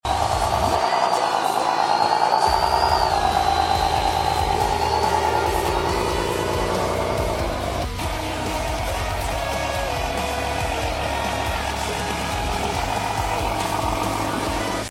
KENWORTH T909 RC TRUCK WITH sound effects free download
KENWORTH T909 RC TRUCK WITH BRUTAL V8 SOUND